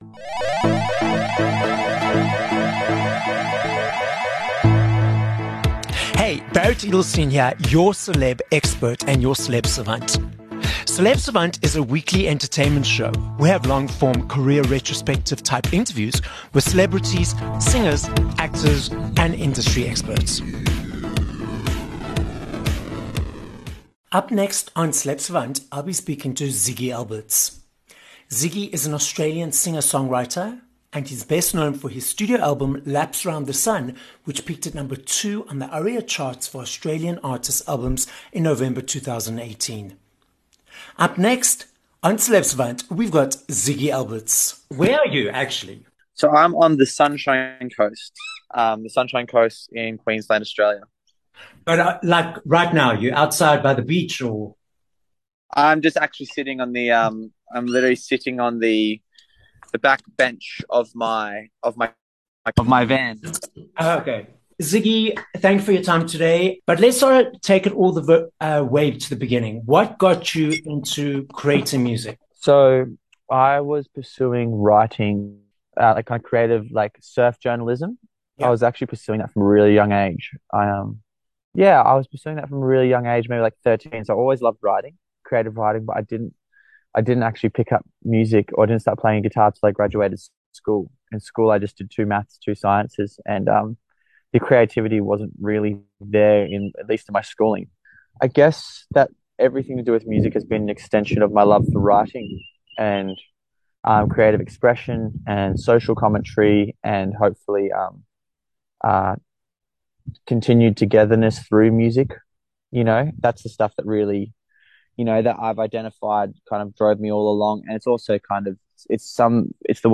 1 Nov Interview with Ziggy Alberts